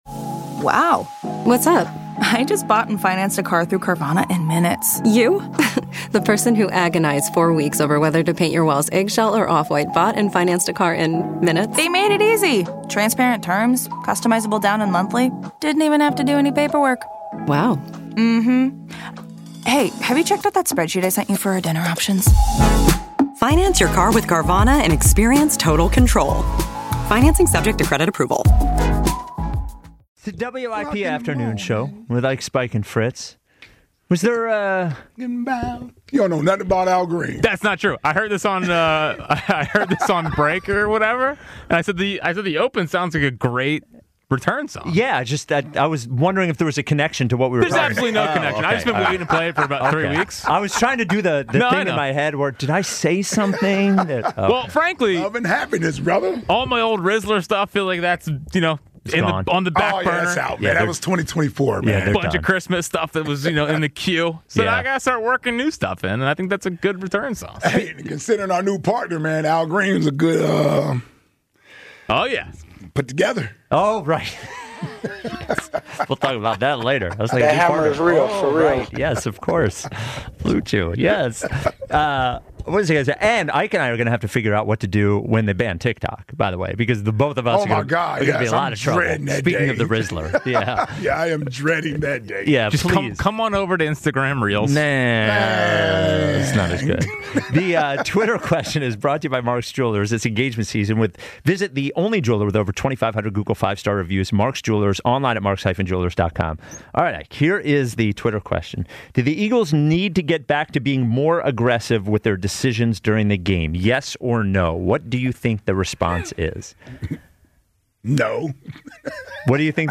The guys take your calls as they talk about Nick Sirianni and Jalen Hurts' decision making. Do the Eagles need to be more aggressive on offense?